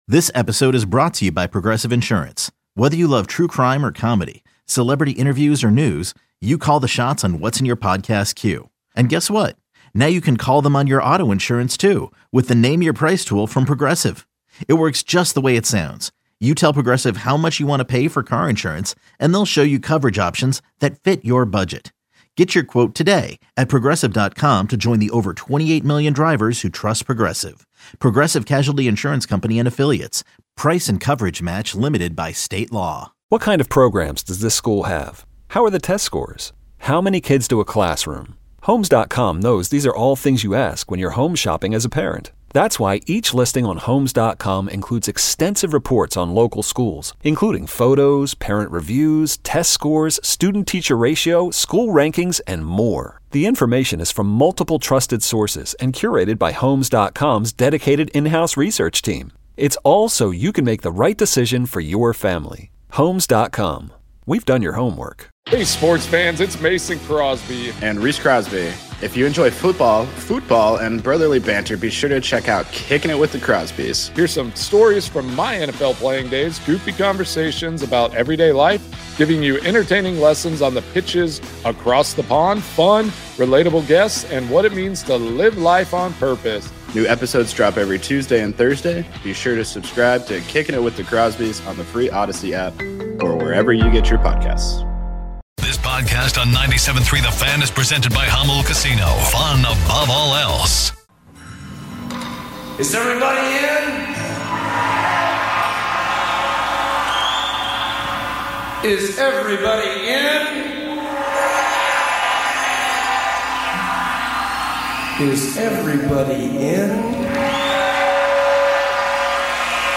Listen here for big moments from the show, weekdays, 6AM - 10AM PT on 97.3 The Fan.